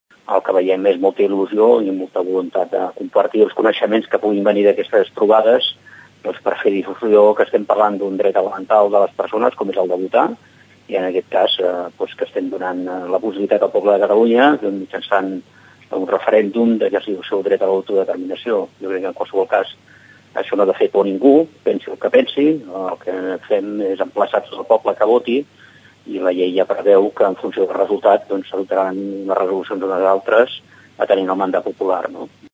L’escoltem en declaracions a Ràdio Tordera.